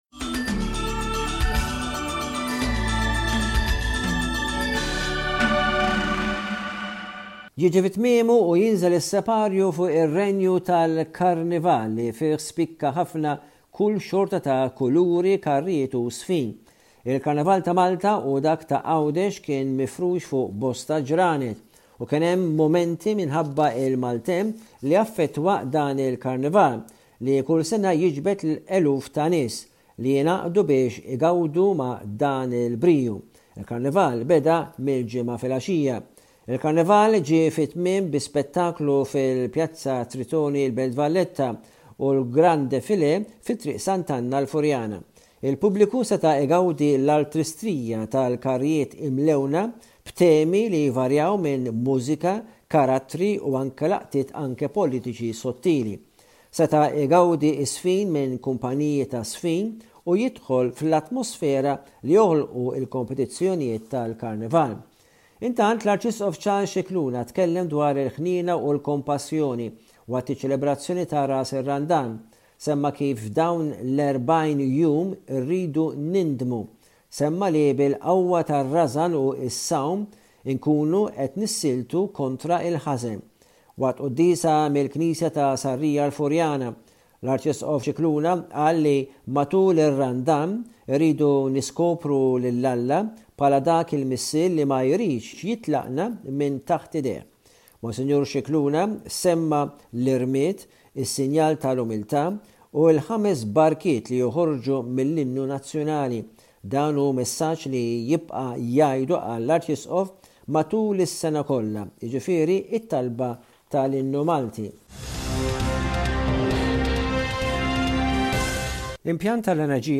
Bullettin ta' aħbarijiet minn Malta